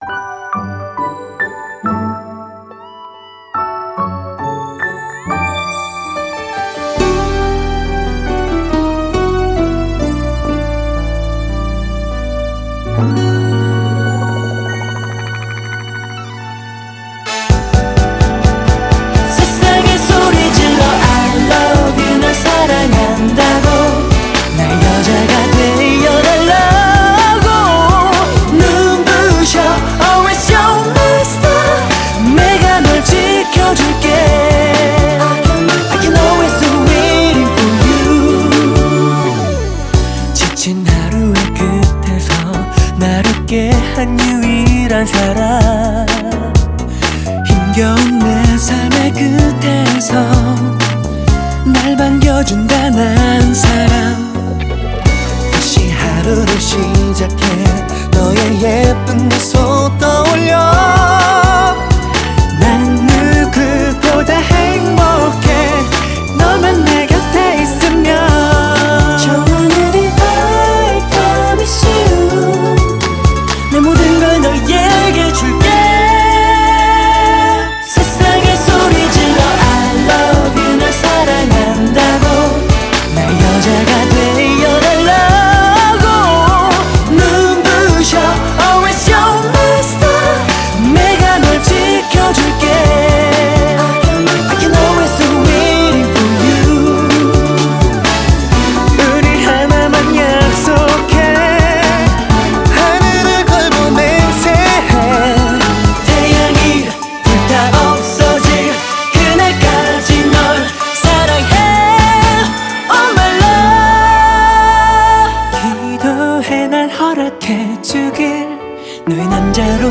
BPM127
Audio QualityPerfect (High Quality)